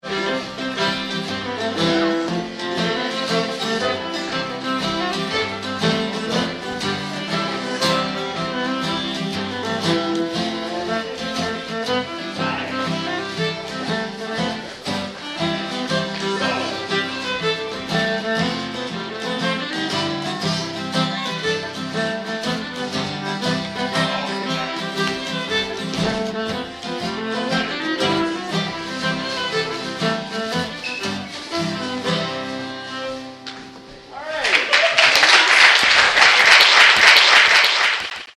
viola
guitar